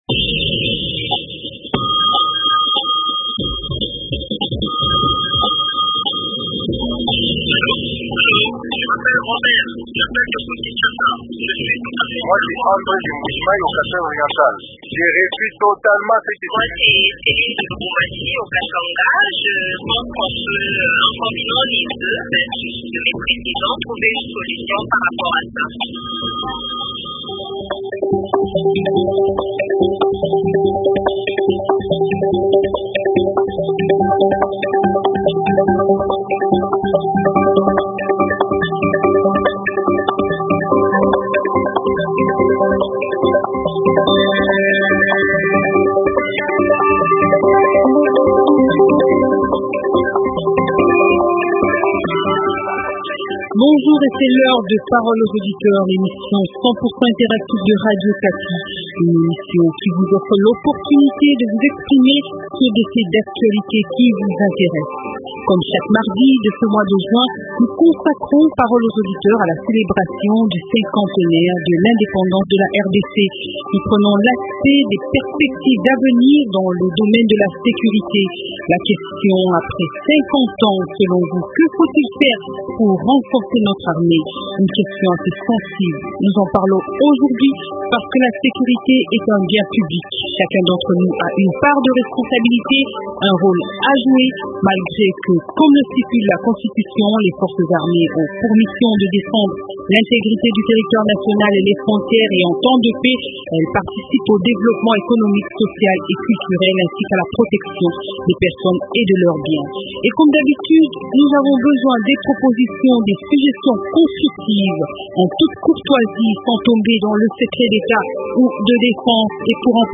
Selon vous que faire pour renforcer notre armée et améliorer la situation sécuritaire en RDC? Invité : Mohamet Bule, député national et général à la retraite des Forces armées de la RDC (FARDC) Intervenant : Jacques Djoli, sénateur et auteur d’une question orale au ministre de la Défense avec débat sur la reforme de l’armée congolaise.